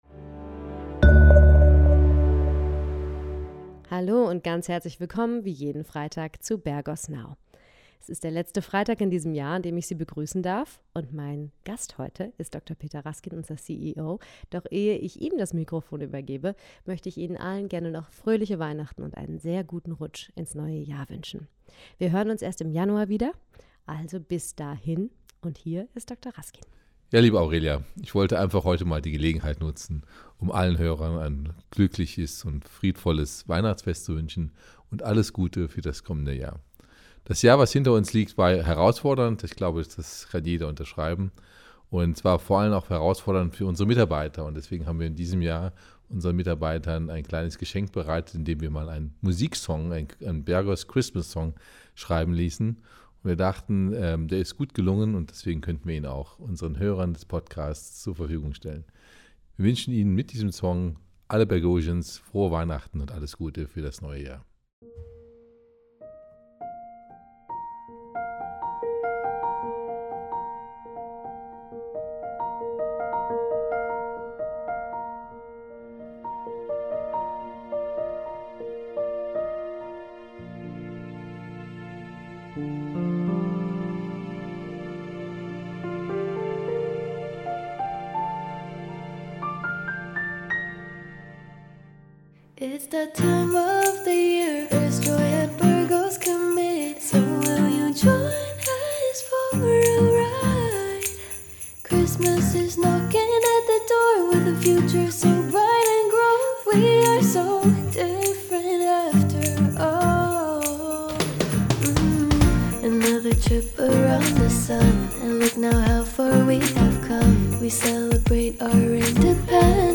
In dieser Sonderepisode wünschen wir Ihnen von ganzem Herzen frohe Weihnachten und präsentieren unseren Bergos Weihnachtssong